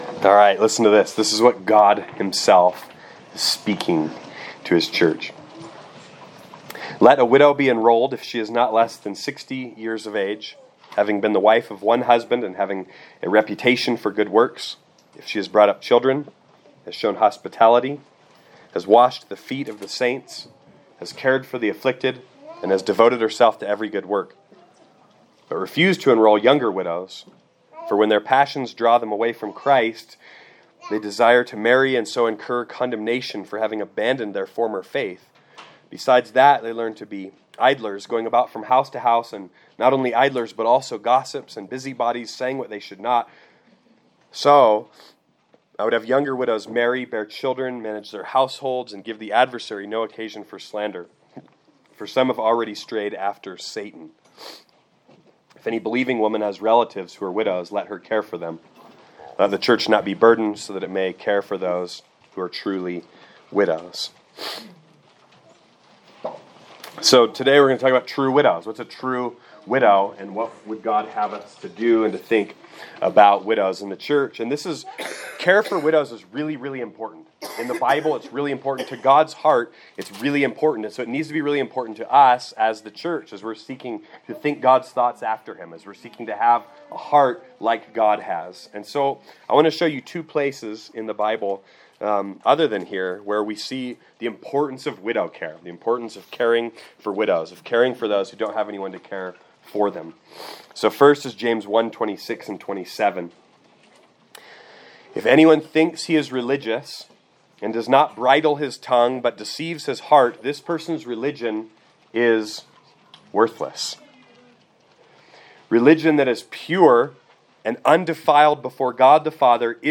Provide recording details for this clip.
Location: Gospel Church Durango